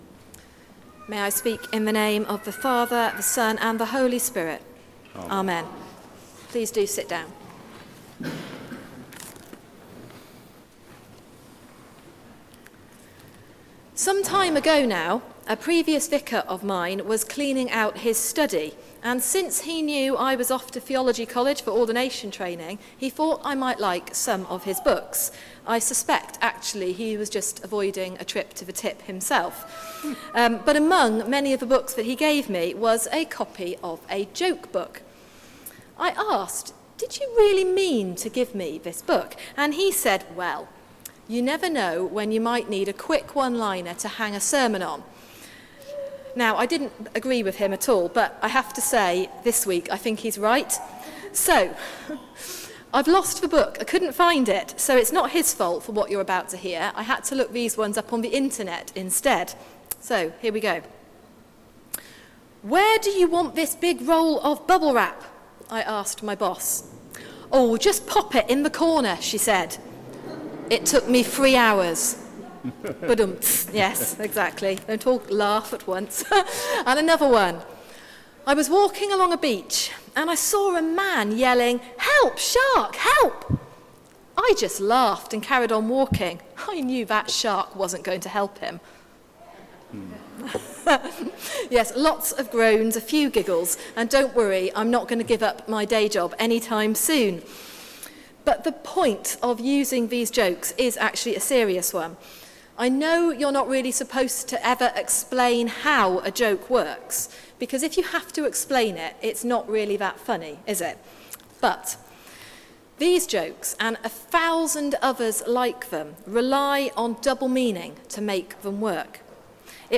Sermon: Born (again?) from above | St Paul + St Stephen Gloucester